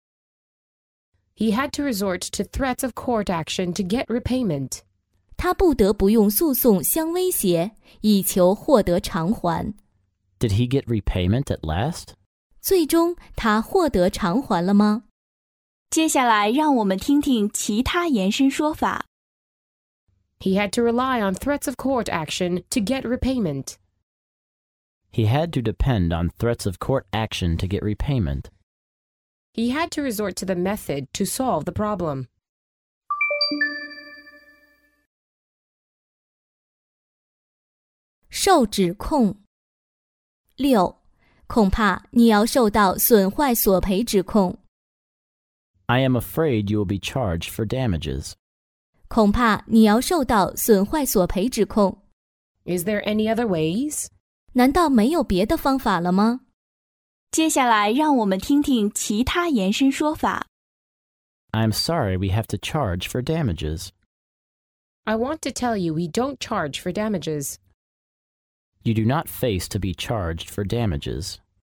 在线英语听力室法律英语就该这么说 第73期:他不得不用诉讼相威胁以求获得偿还的听力文件下载,《法律英语就该这么说》栏目收录各种特定情境中的常用法律英语。真人发音的朗读版帮助网友熟读熟记，在工作中举一反三，游刃有余。